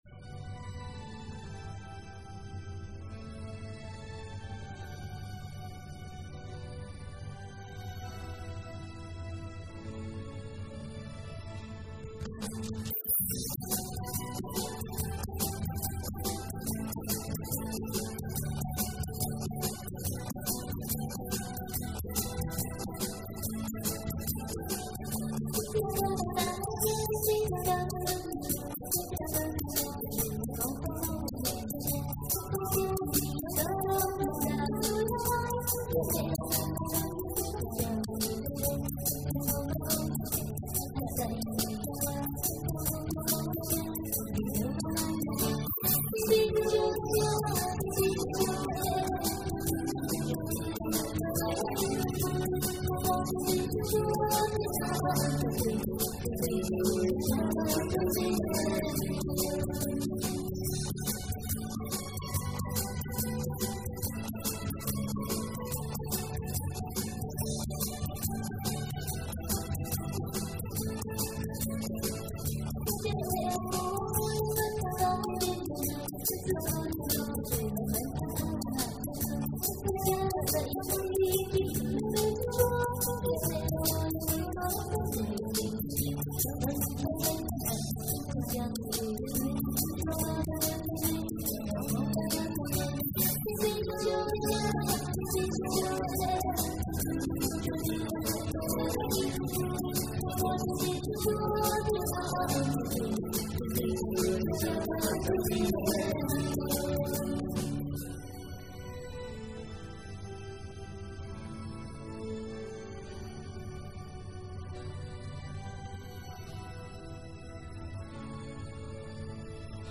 thể hiện hơn 90 bài hát Thánh Ca.